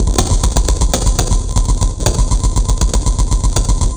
Abstract Rhythm 19.wav